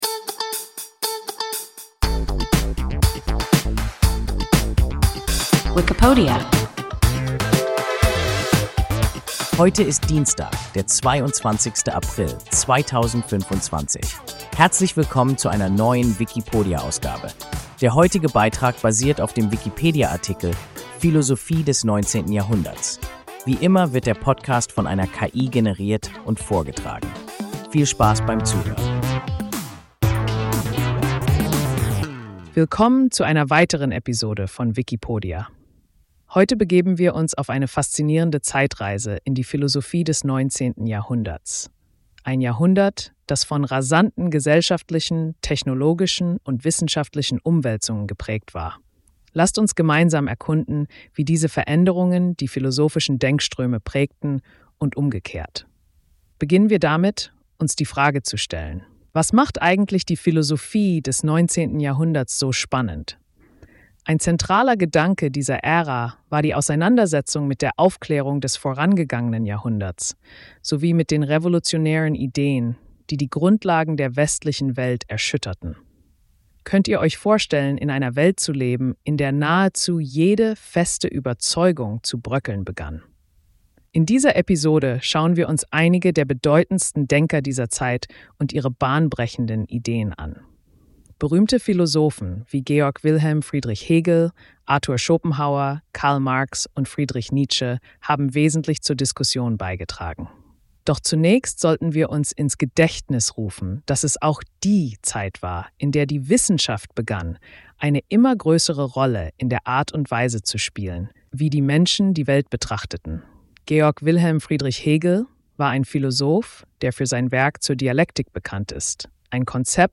Philosophie des 19. Jahrhunderts – WIKIPODIA – ein KI Podcast